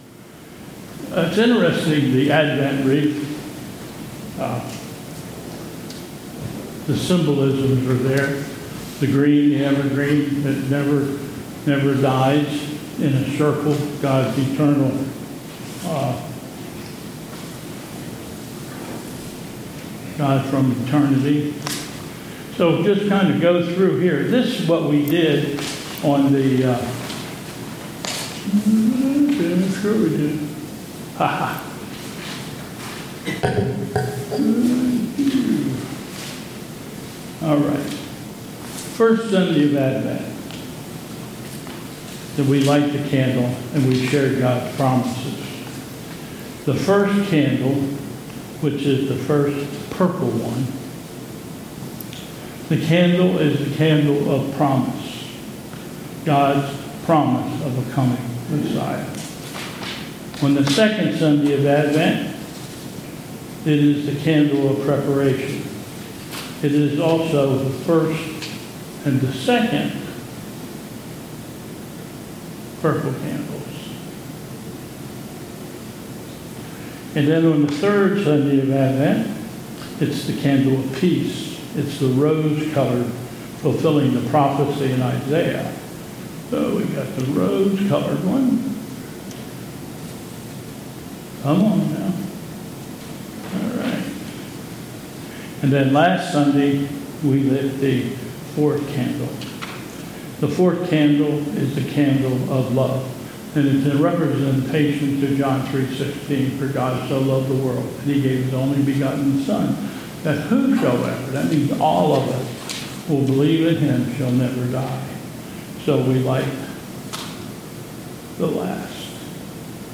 Christmas Eve
Reading "The Most Wonderful Time of the Year